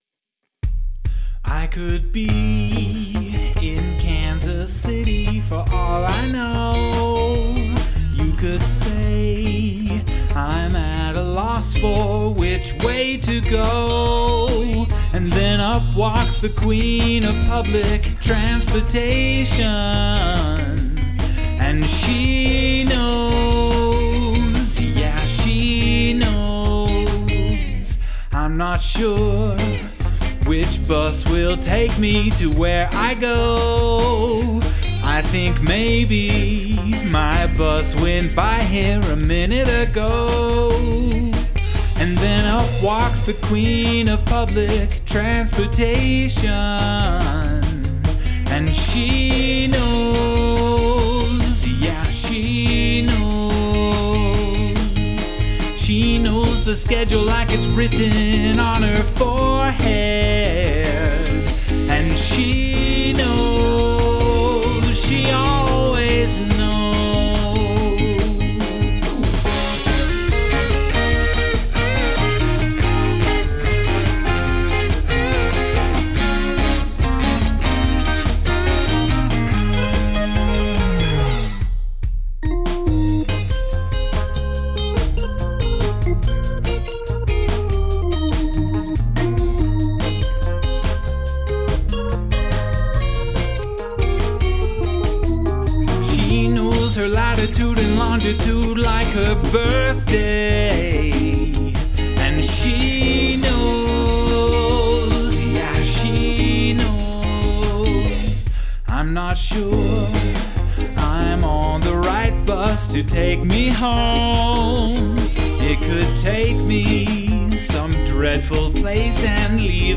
vocals, guitars, drums
vocals, organ, bass, electric piano, horns